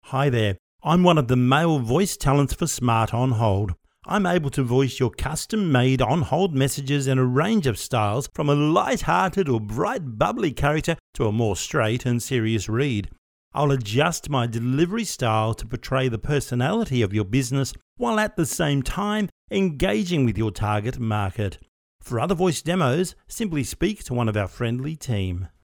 Professional Voice Over Library – Voice Samples
Smart On Hold – Male Voice Overs
MALE 3